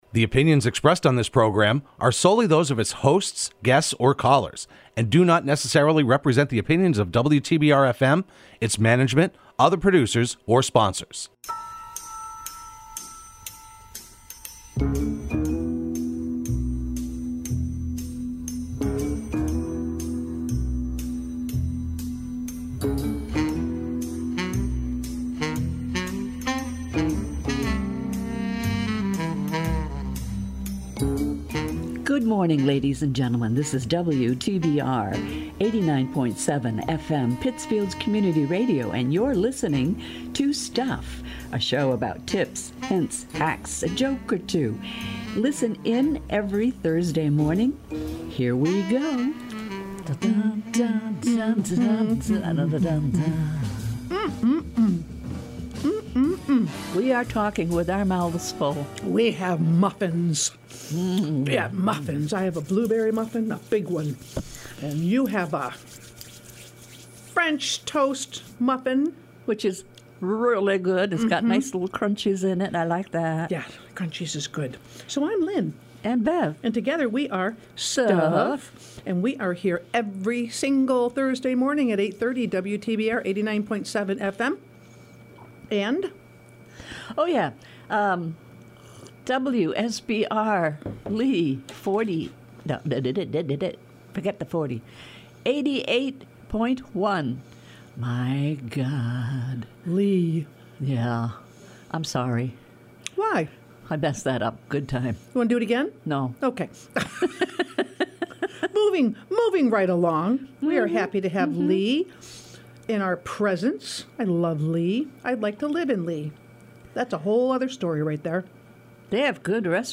Broadcast every Thursday morning at 8:30am on WTBR.